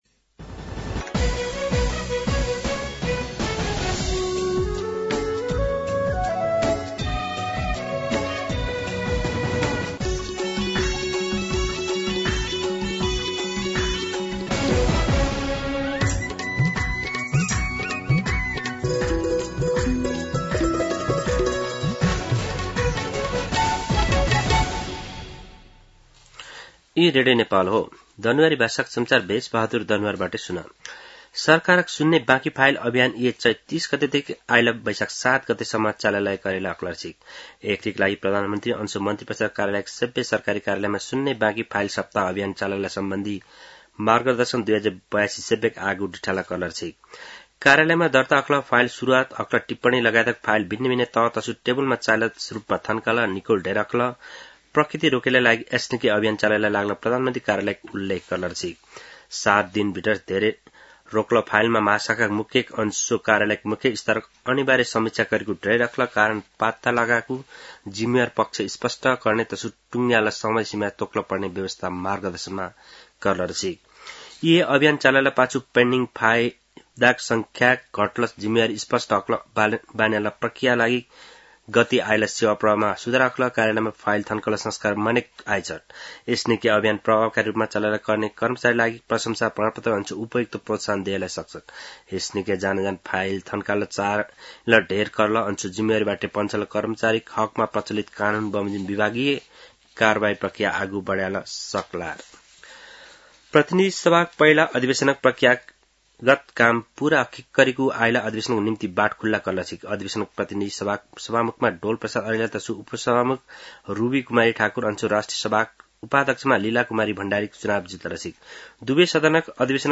दनुवार भाषामा समाचार : २८ चैत , २०८२
Danuwar-News-28.mp3